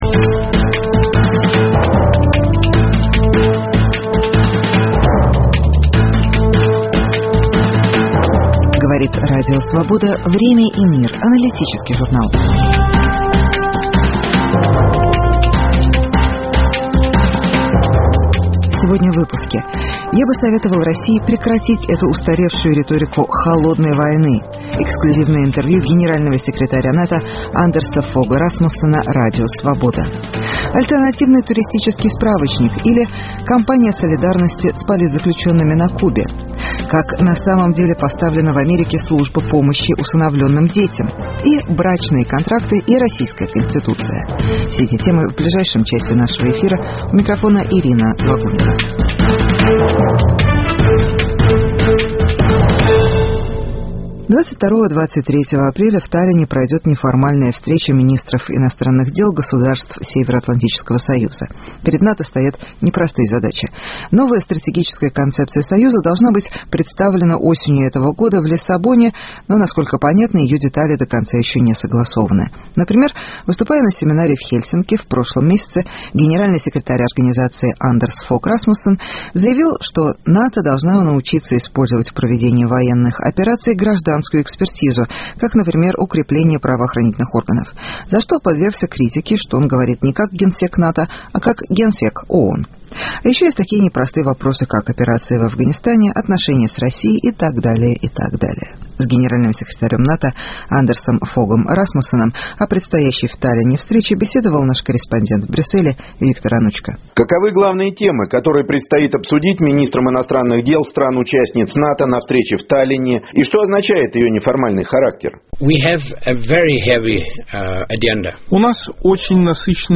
Почему генсек НАТО советует России отказаться от риторики «холодной войны». Интервью с Андерсом Фогом Расмуссеном. Испания: кампания солидарности с кубинскими диссидентами.